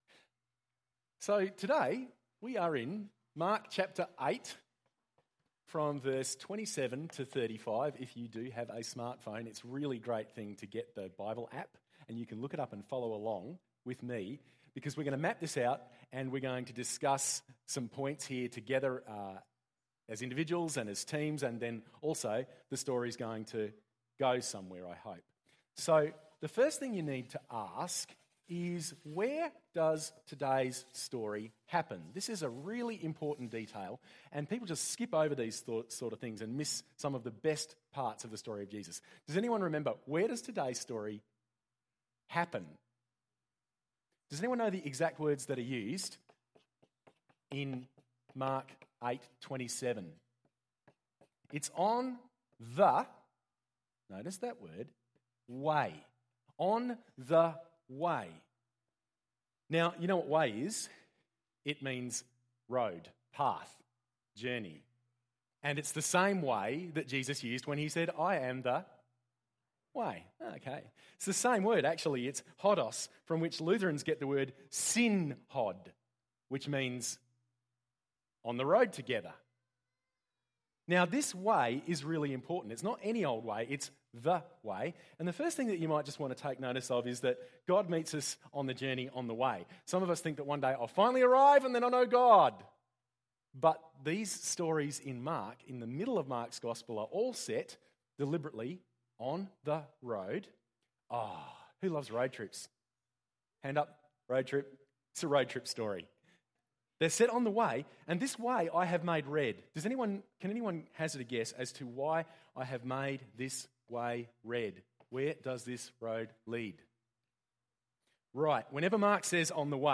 It’s all black-board sermons at the moment, so this was drawn as we talked…